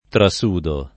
trasudare v.; trasudo [ tra S2 do ]